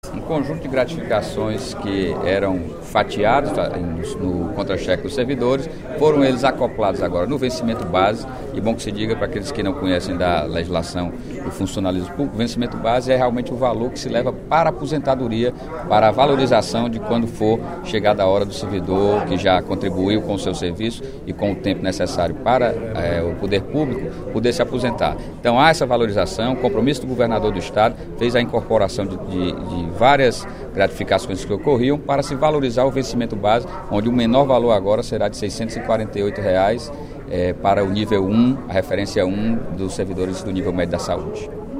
A pedido do deputado Sérgio Aguiar (PSB), durante pronunciamento no primeiro expediente da sessão plenária desta sexta-feira (28/12), as duas mensagens do Executivo, 7.455 e 7.456, lidas no expediente de hoje, serão apreciadas em regime de urgência pela Assembleia Legislativa.